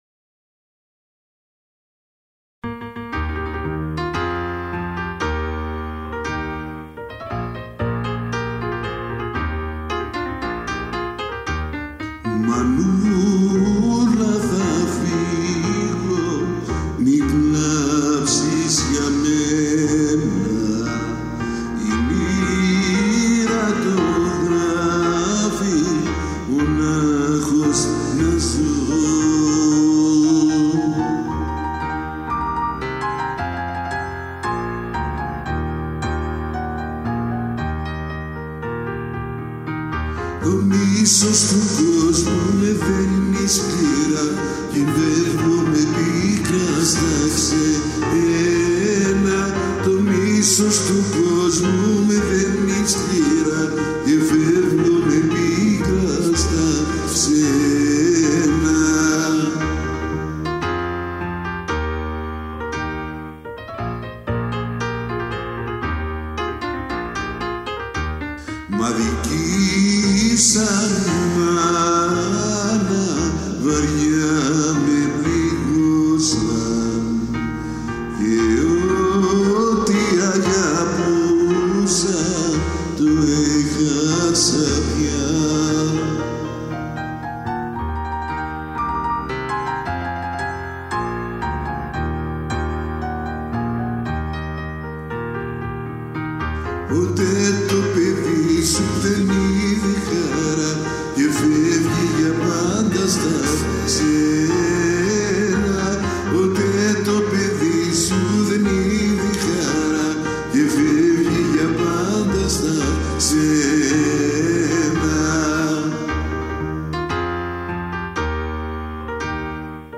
Below are musical recordings of me playing music.